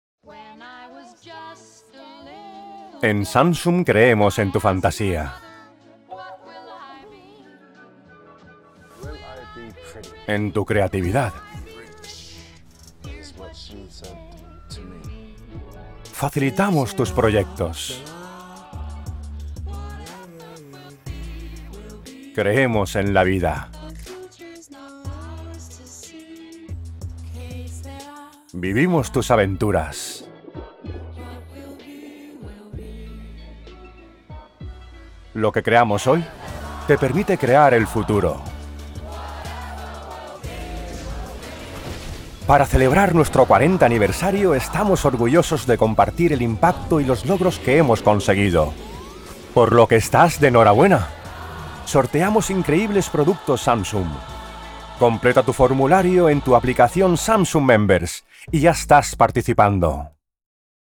HOMBRES (de 35 a 50 años)